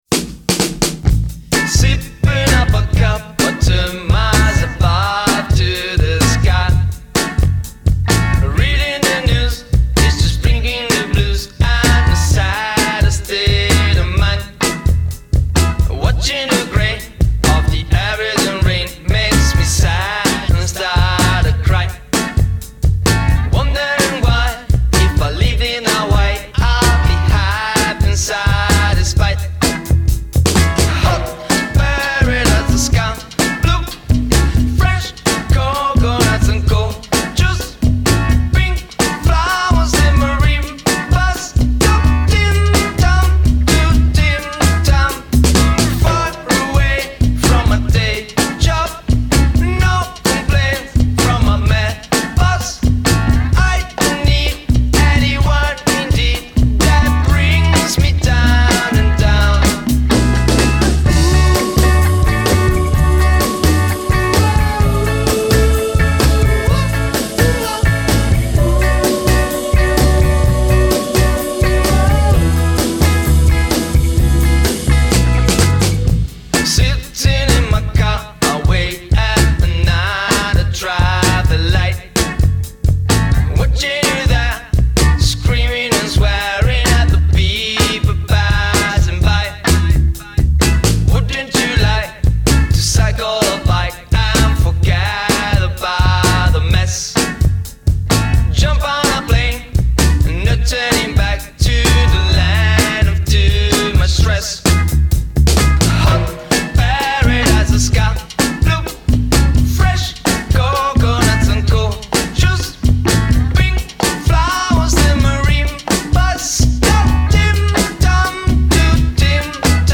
some Britpop anthems